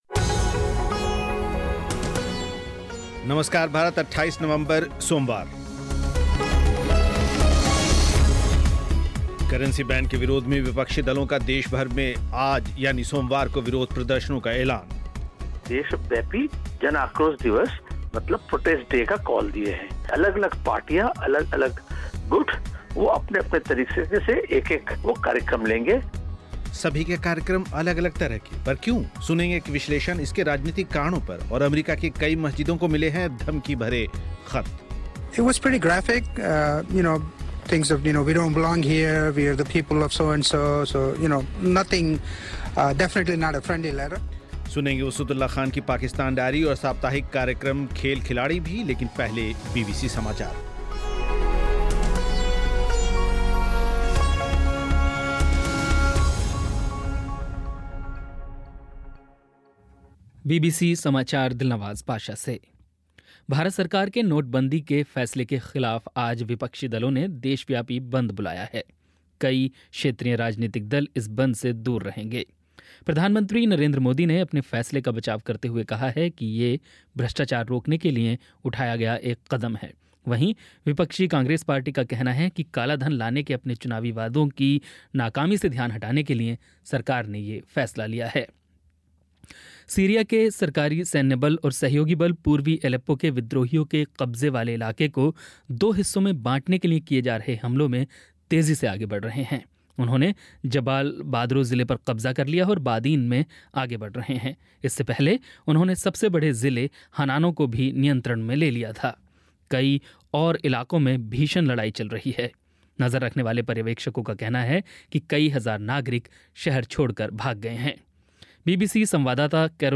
बीबीसी समाचार